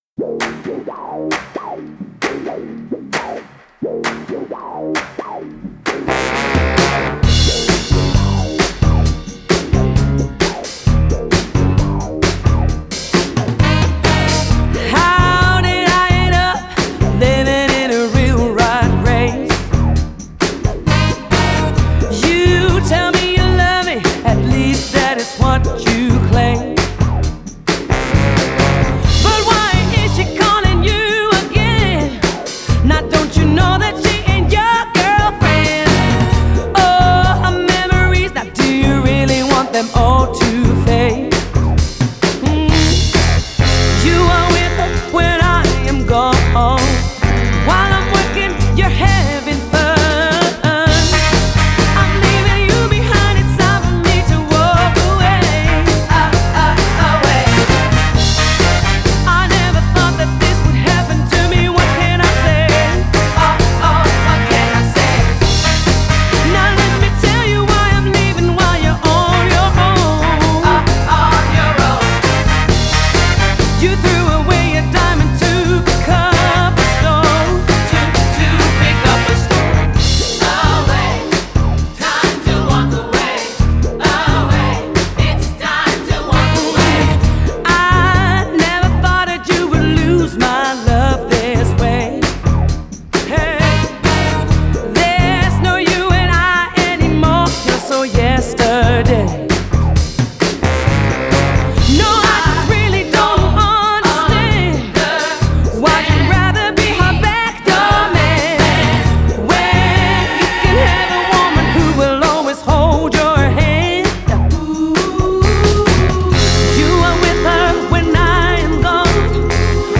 Genre: Pop / Soul / Jazz